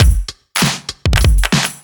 OTG_Kit7_Wonk_130b.wav